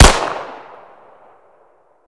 glock17_shoot.ogg